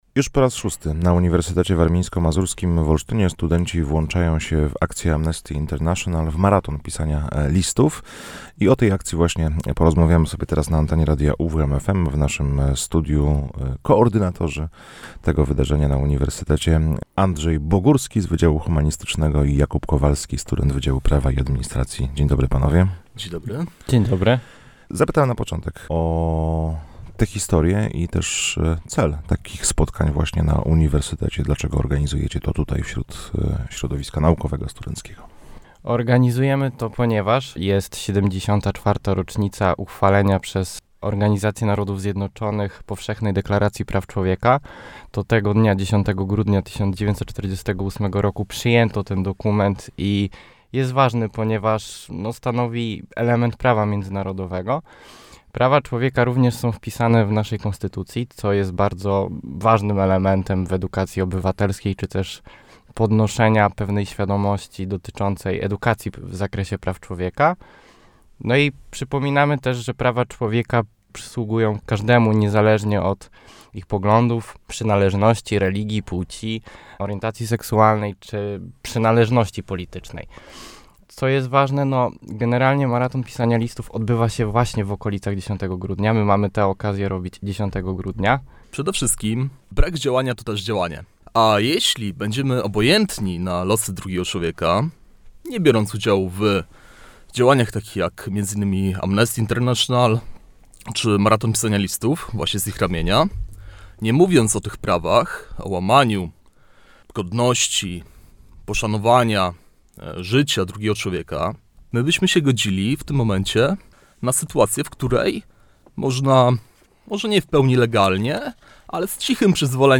Już po raz szósty Uniwersytet Warmińsko-Mazurski w Olsztynie będzie jednym z miejsc, w których odbywa się Maraton Pisania Listów Amnesty International. 10 grudnia - czyli w Międzynarodowym Dniu Praw Człowieka - na Wydziale Humanistycznym zaplanowano także prelekcje poświęcone tej tematyce. O czym w studiu Radia UWM FM opowiadali